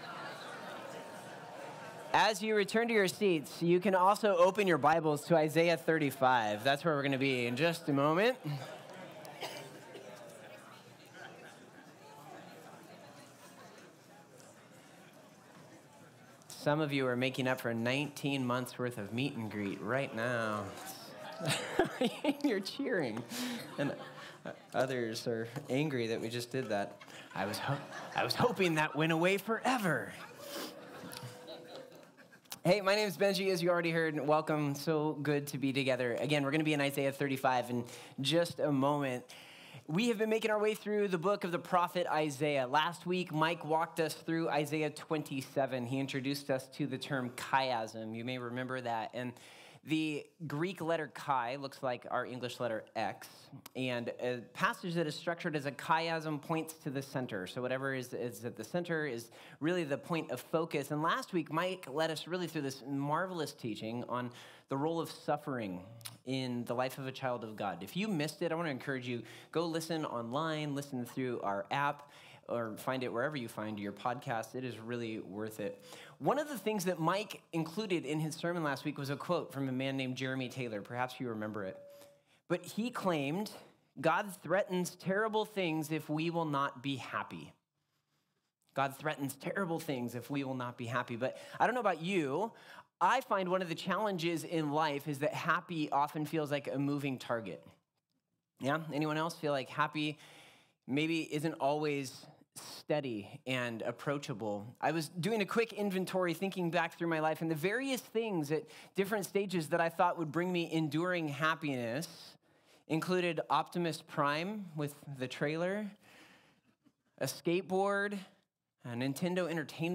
Isaiah Passage: Isaiah 35 Service Type: Sunday Topics